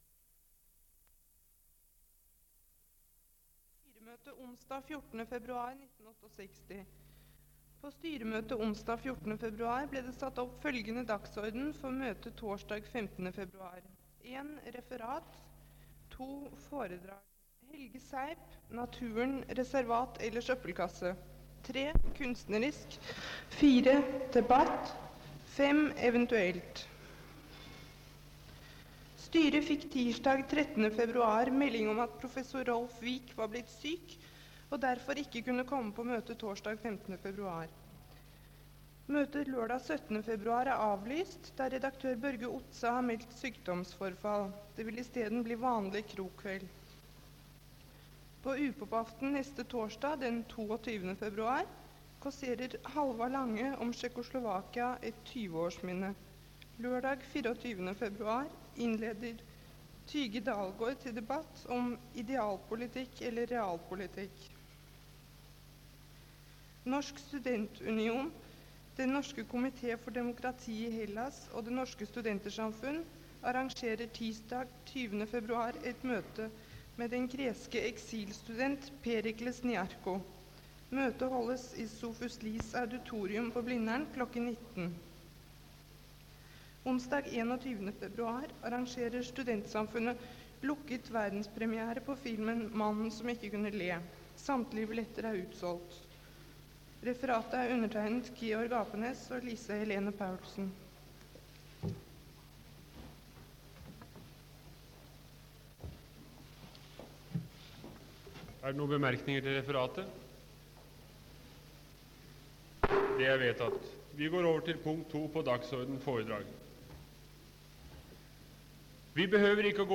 Det Norske Studentersamfund, Lydbånd og foto, Foredrag, debatter, møter, nr. 33.5: Helge Seip, "Naturvern", 15.2.1968